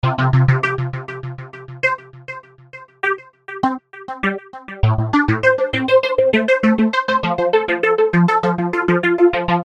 电钻
描述：电钻在工作中
Tag: 电钻 电动 电钻 工具